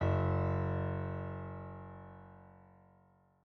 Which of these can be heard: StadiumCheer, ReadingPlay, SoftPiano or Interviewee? SoftPiano